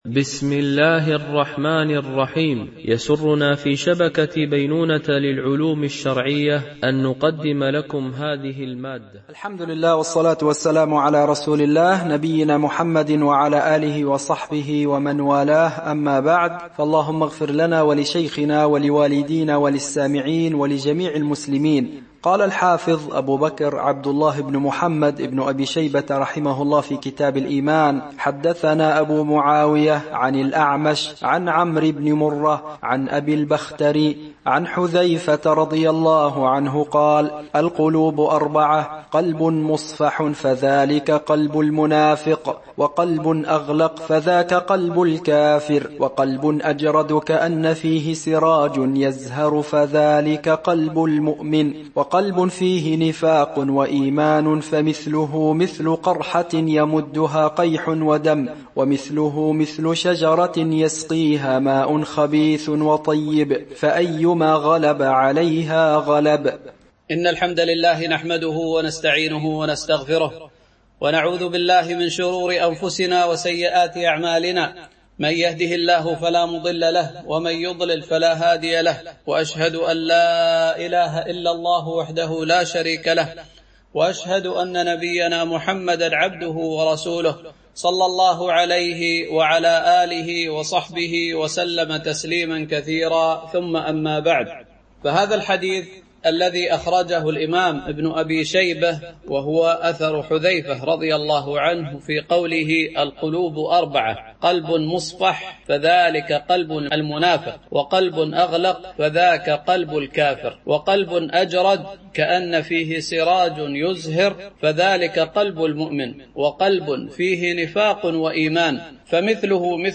شرح كتاب الإيمان لابن أبي شيبة ـ الدرس 17 (الحديث 54)